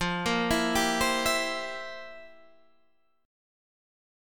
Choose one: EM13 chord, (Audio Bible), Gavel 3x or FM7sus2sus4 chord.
FM7sus2sus4 chord